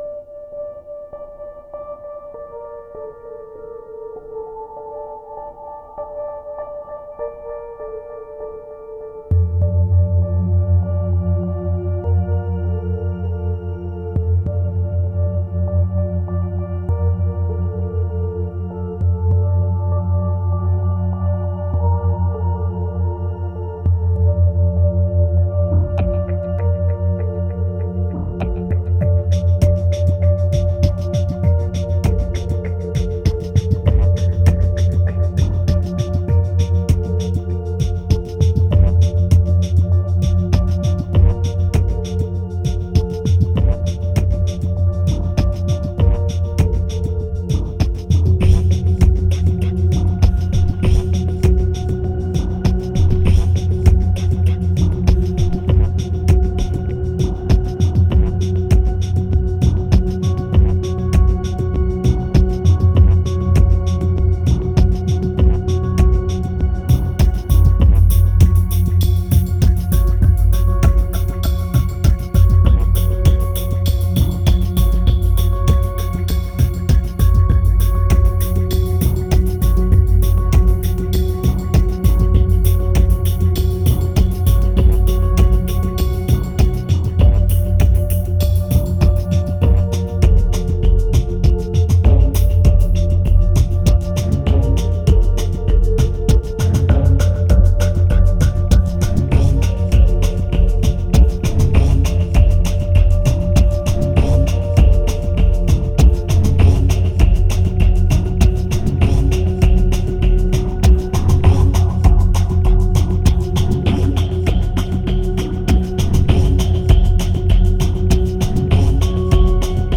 1816📈 - 59%🤔 - 99BPM🔊 - 2013-06-29📅 - 171🌟